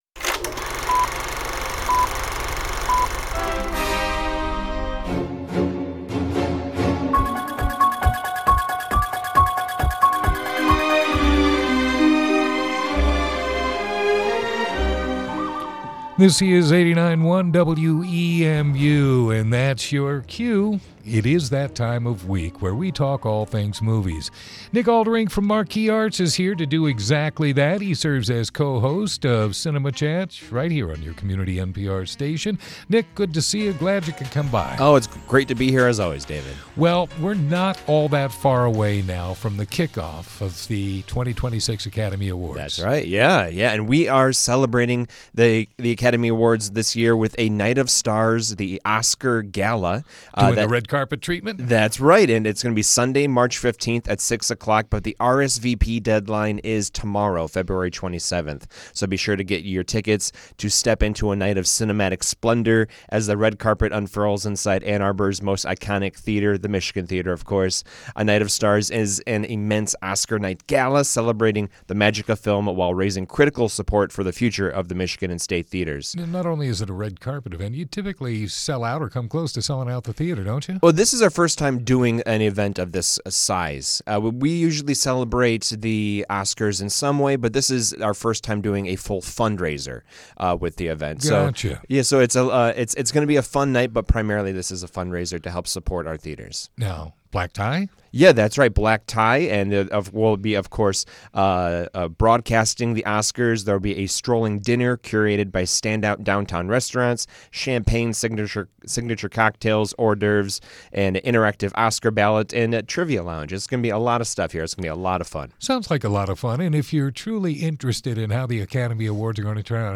a most entertaining conversation on movies, culture and live events to be experienced in the local area.